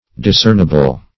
Discernible \Dis*cern"i*ble\, a. [L. discernibilis.]